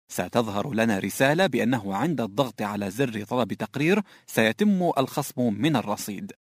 Arabian voiceover talent with warm and confident voice
Sprechprobe: eLearning (Muttersprache):